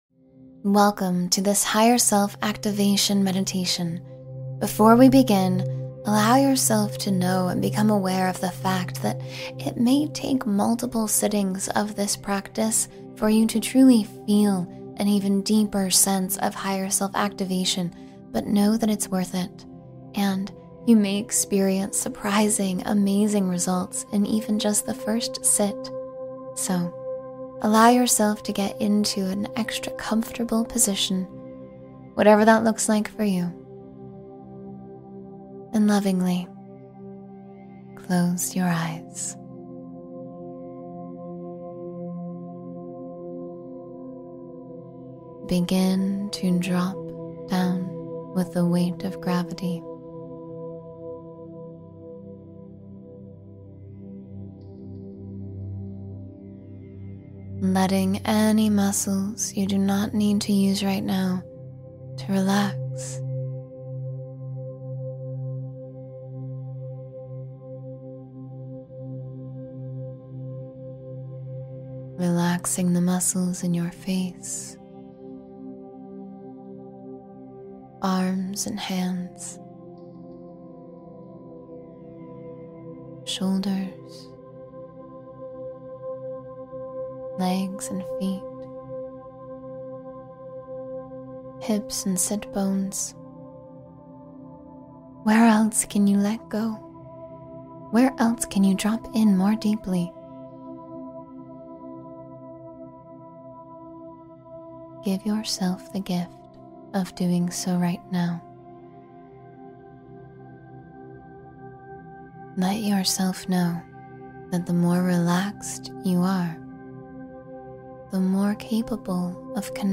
Awake Gently and Welcome the Day — Guided Meditation for a Peaceful Start